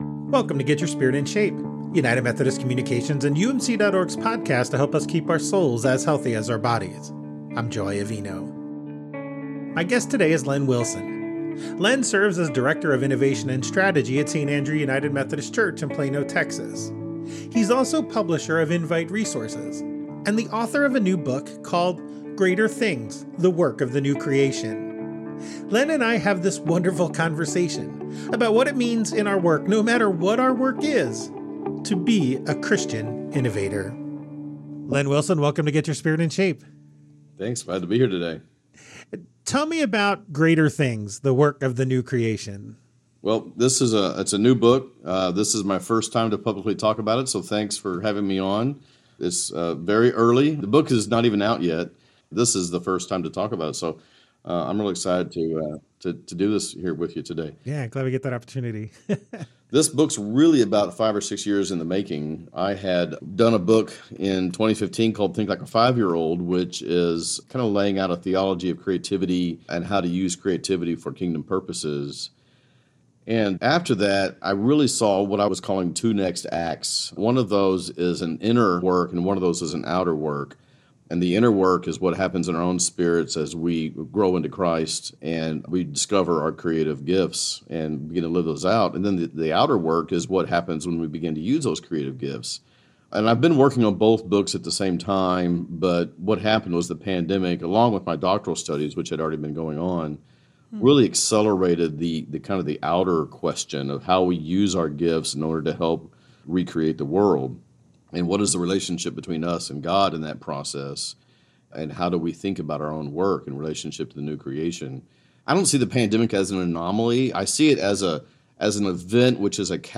Help us spread the word Tell others: members of your church, coworkers, and anyone else might benefit from these conversations.